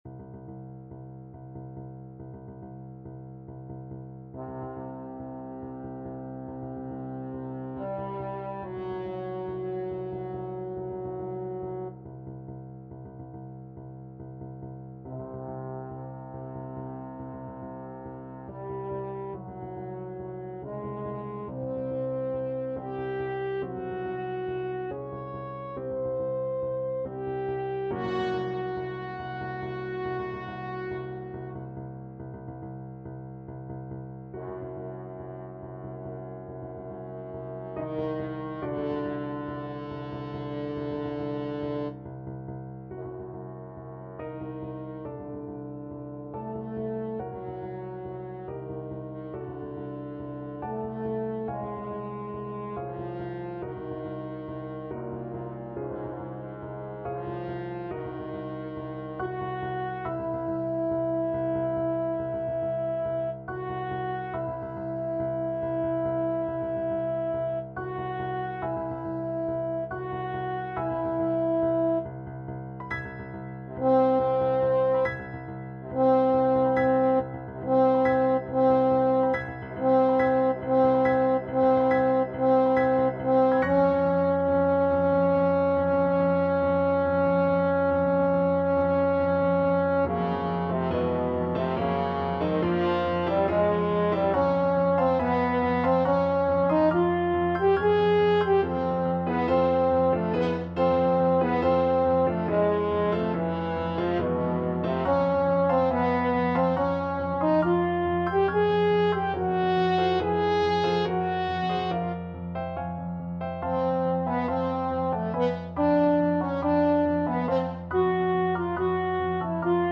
French Horn
5/4 (View more 5/4 Music)
D minor (Sounding Pitch) A minor (French Horn in F) (View more D minor Music for French Horn )
Allegro = 140 (View more music marked Allegro)
Classical (View more Classical French Horn Music)